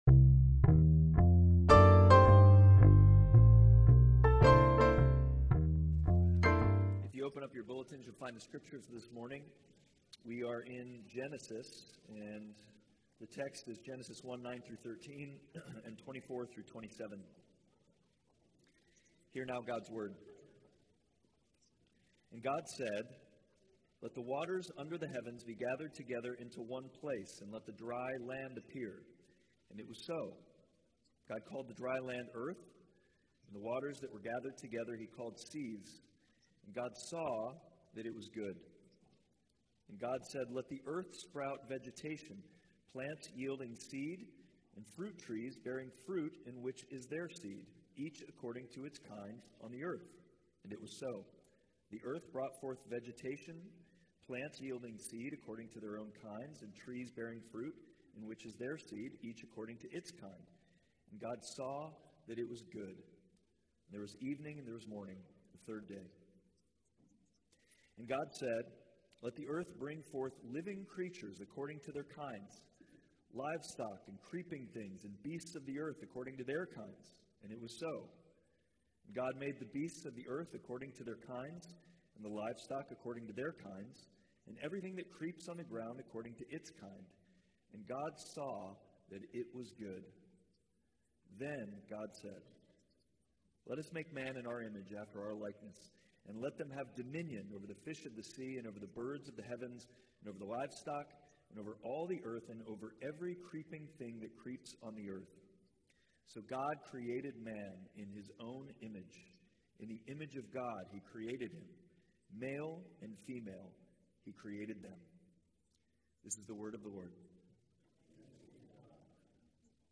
Passage: Genesis 1:9-13, 24-27 Service Type: Sunday Worship